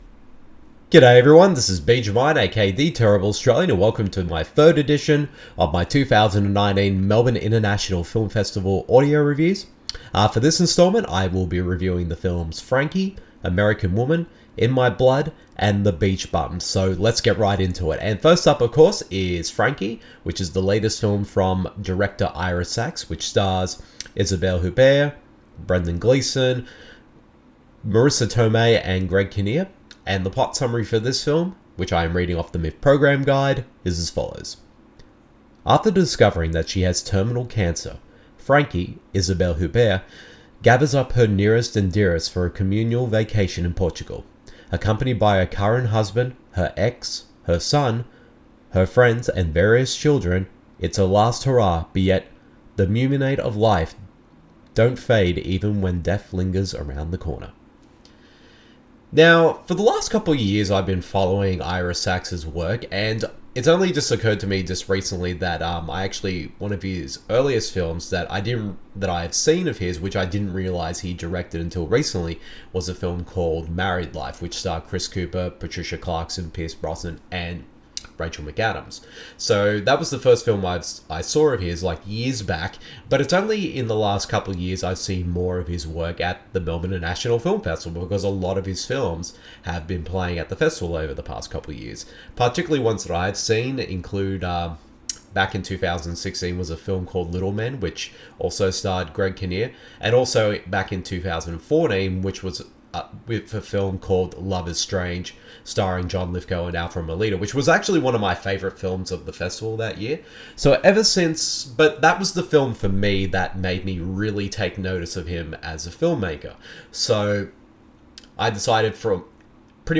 Audio reviews